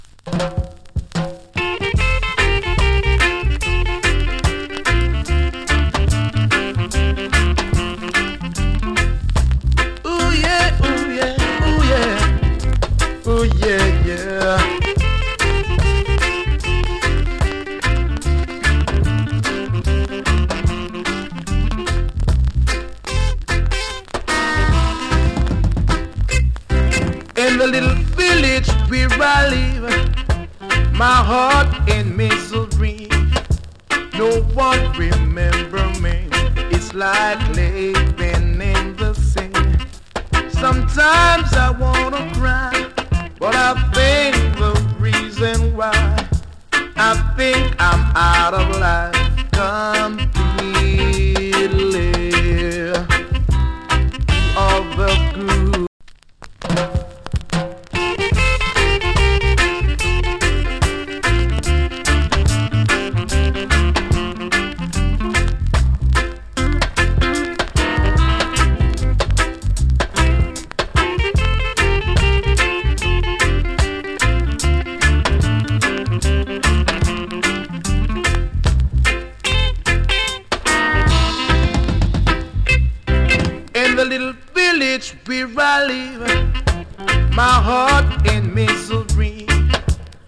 チリ、パチノイズ少々有り。音にヒズミわずかに有り。
NICE VOCAL REGGAE !